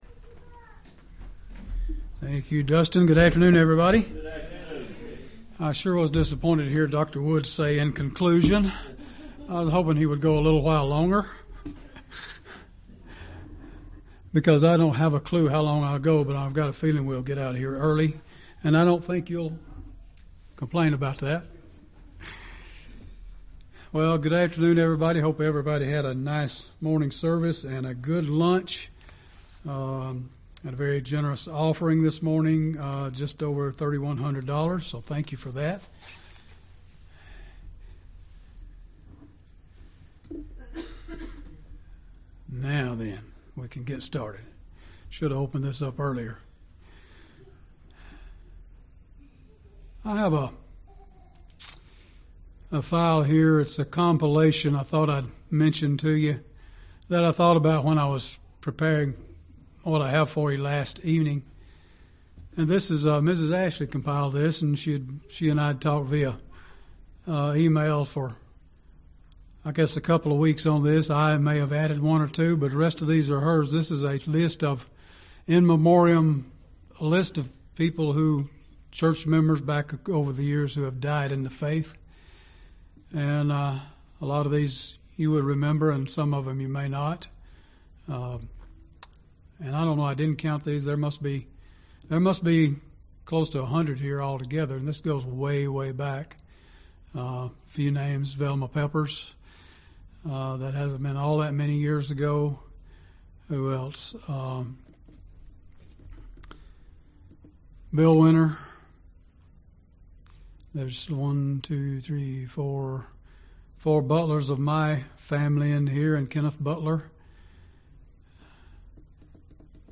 This sermon speaks of how we, as God's people, can be sure that we will have the faith to finish our race.
Given in Gadsden, AL